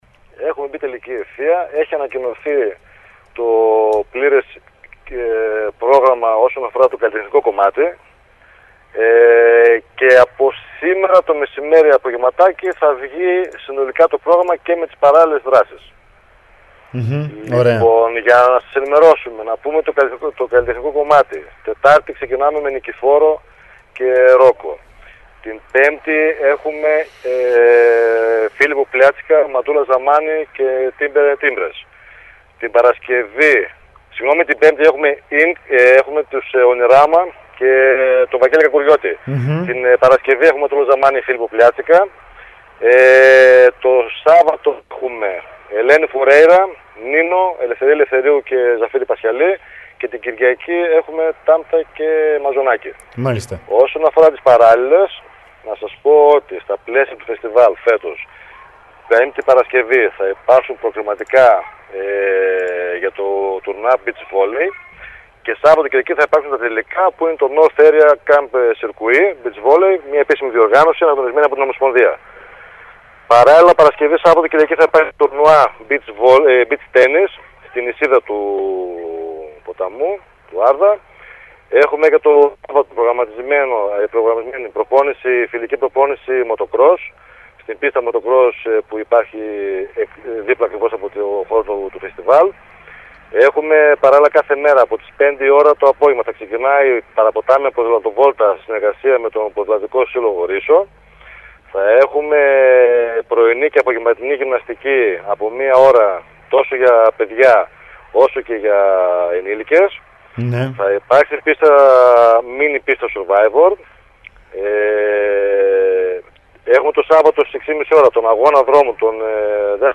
στο ραδιόφωνο Sferikos 99,3